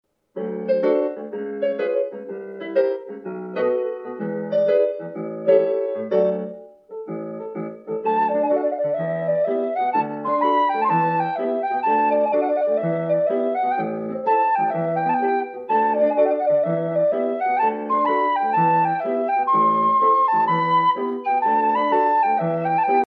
- original irische Verzierungstechniken